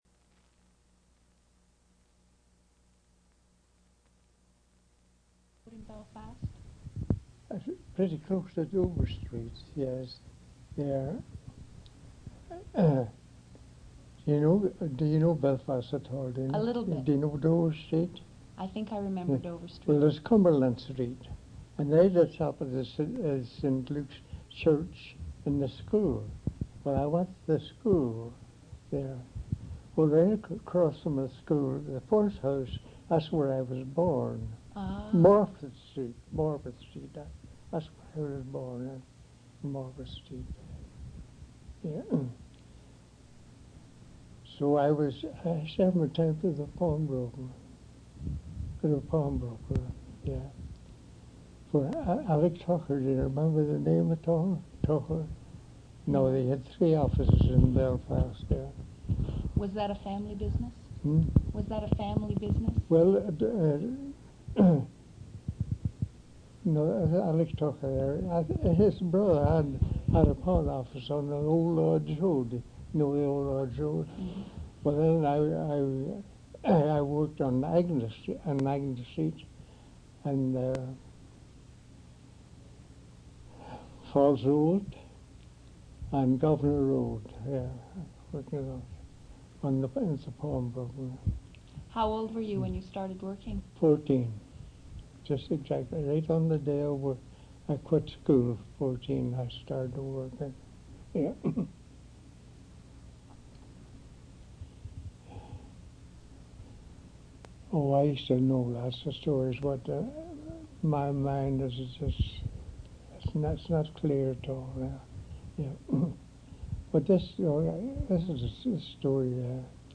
One original sound cassette (ca. 60 min.) : 2 track, mono. ; 1 sound cassette copy : standard, mono.